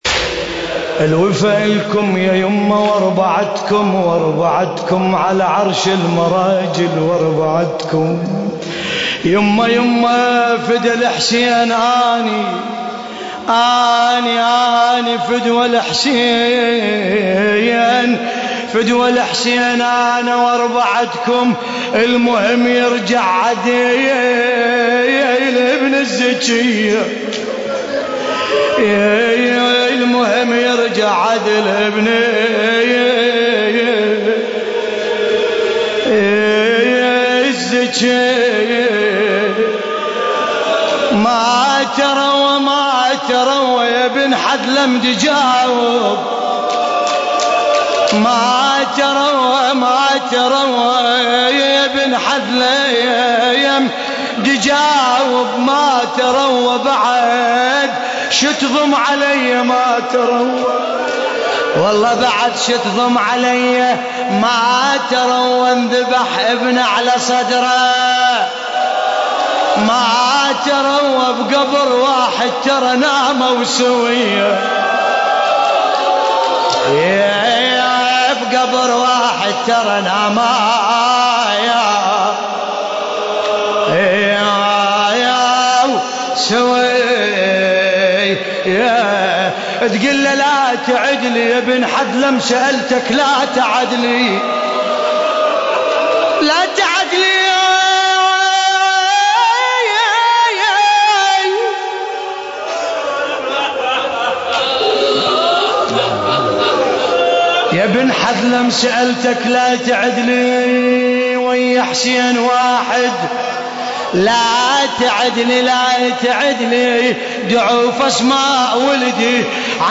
القارئ: باسم الكربلائي التاريخ: ليلة 4 محرم الحرام 1434 هـ - مسجد أبو الفضل العباس عليه السلام - الكويت.